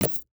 UIMvmt_Menu_Slide_Next_Page_Close 03.wav